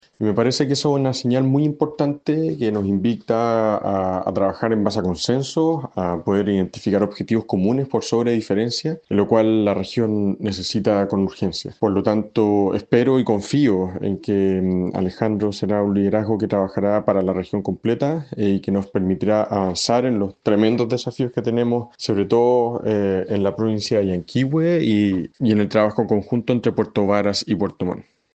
Desde Puerto Varas, el alcalde reelecto, Tomás Garate, sostuvo que la región optó por una opción moderada y que ahora es momento de trabajar en base a consensos y acuerdos por el futuro de la provincia de Llanquihue.